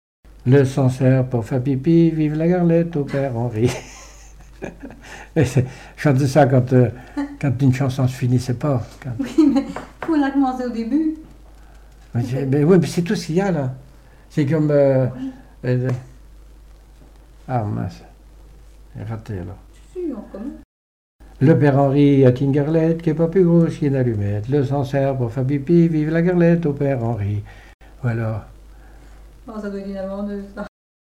chanté quand un chanteur perd la fin de sa chanson
Enfantines - rondes et jeux
Pièce musicale inédite